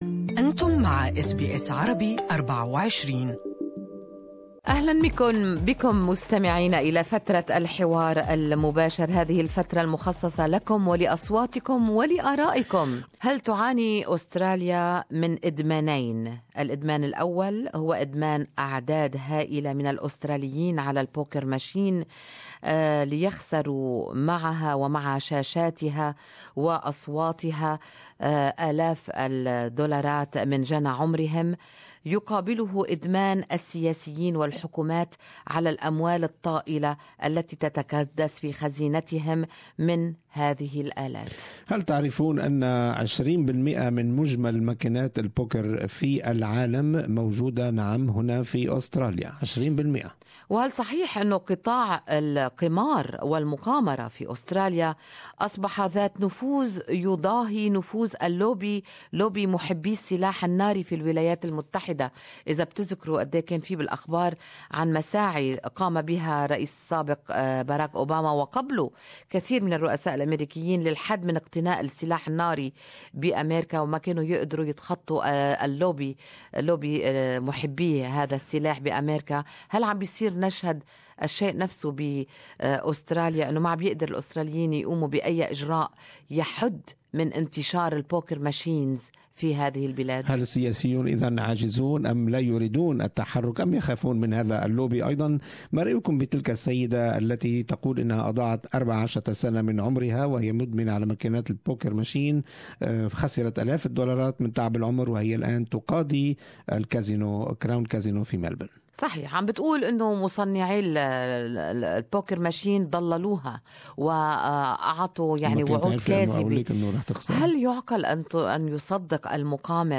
كما شارك بعض المستمعين بخبرات خاصة في هذا المجال.